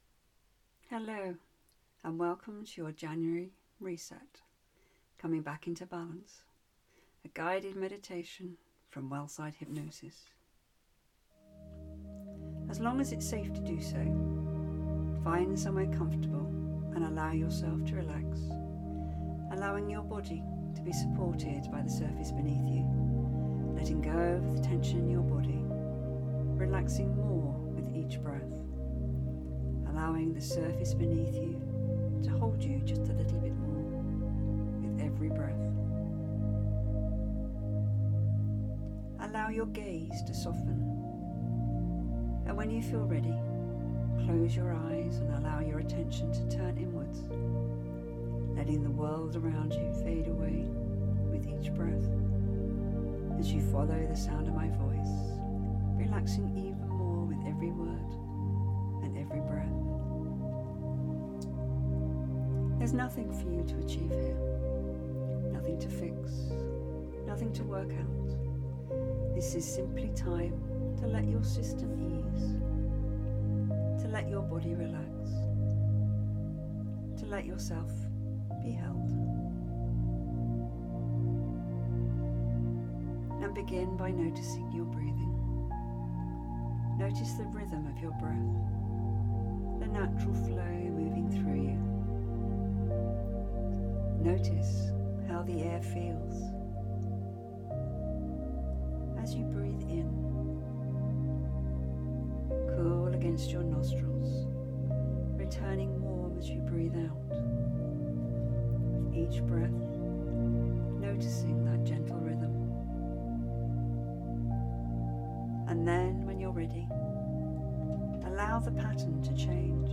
Guided meditation audio for stress and overwhelm
A gentle guided meditation designed to support moments of stress and overwhelm, and help the nervous system settle after a demanding period.